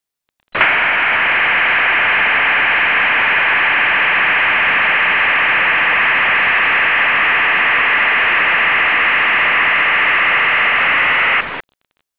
Le velocit� possibili vanno da 75 a 2400 bps, la struttura a principale occupa una larghezza di banda compresa te 300 e 3300 Hz. Il sistema incorpora una sottoportante a 1800 Hz non visibile con un normale analizzatore di spettro. La tecnica di modulazione usata per questa portante � la 8-PSK e ci� permette una velocit� costante di 2400 bps.